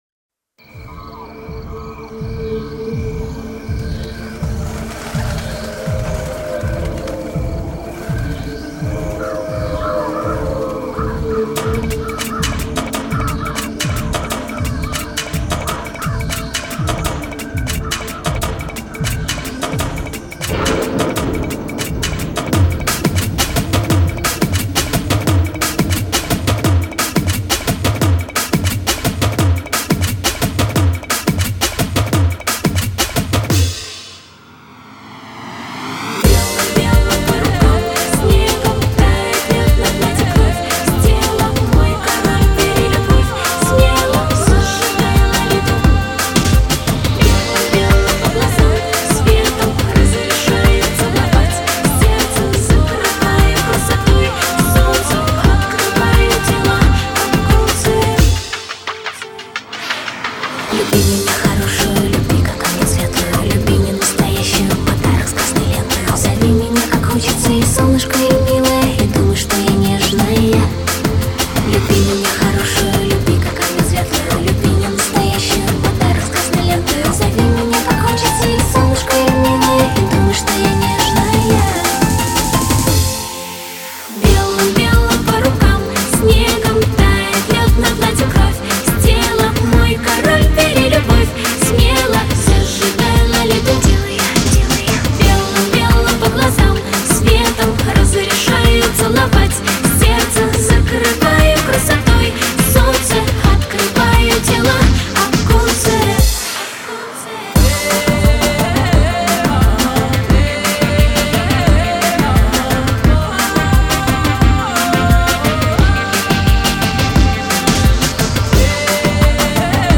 Жанр: Поп Продолжительность: 00:47:21 Список композиций: